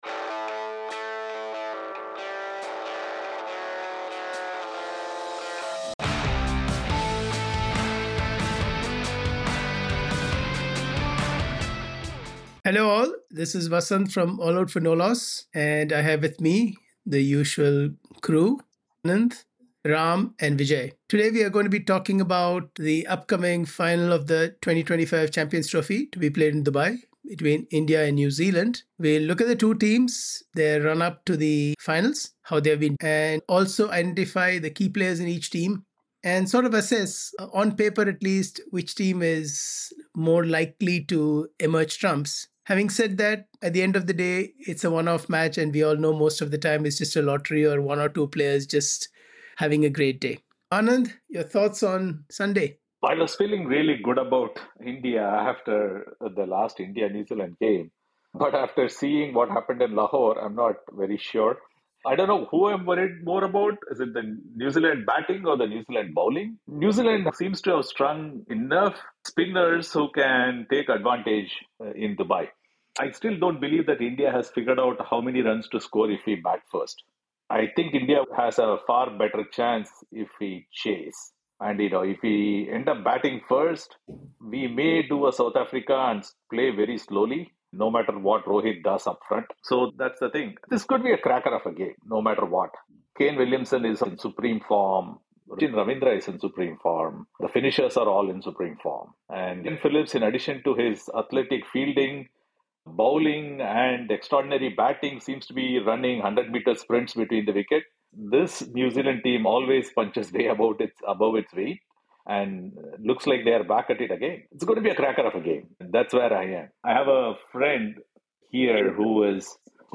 In this conversation we will take a look at the two teams and the lead-up